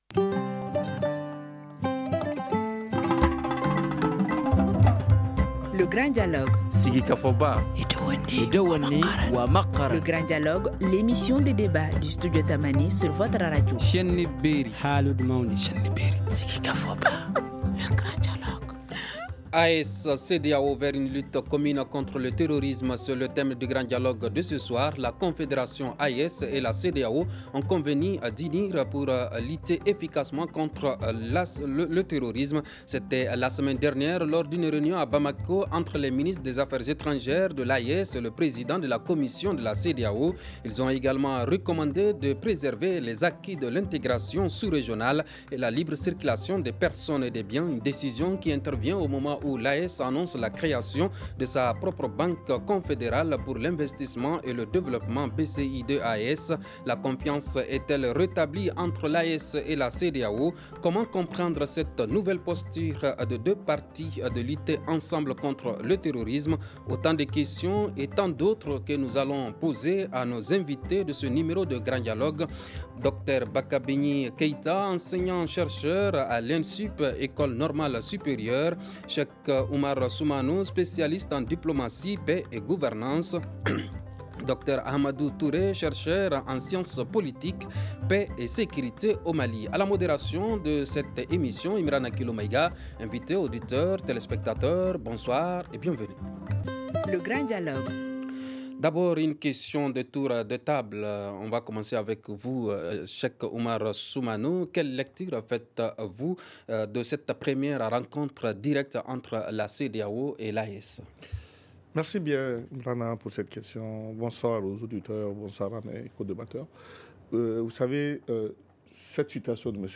La confiance est-elle rétablie entre l’AES et la CEDEAO ? Comment comprendre cette nouvelle posture des deux parties de lutter ensemble contre le terrorisme ? Autant de question et tant d’autres que nous allons poser à nos invités de ce numéro de Grand Dialogue